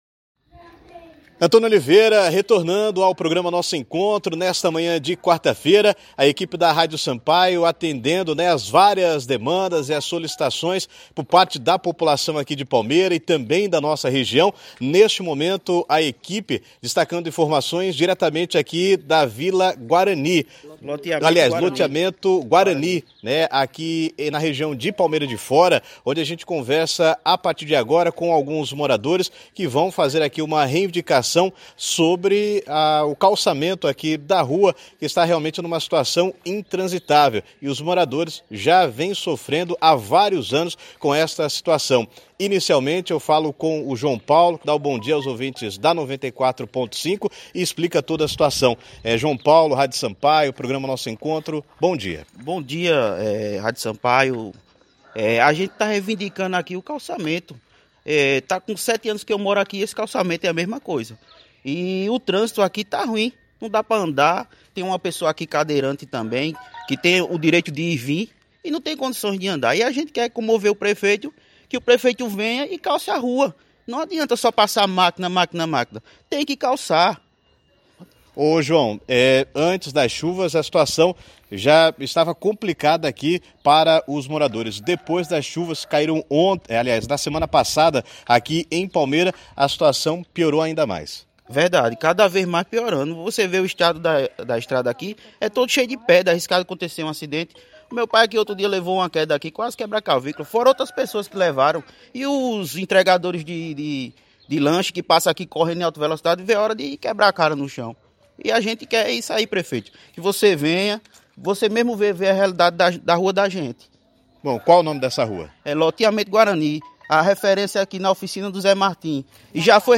Ouça a entrevista completa com o relato dos moradores: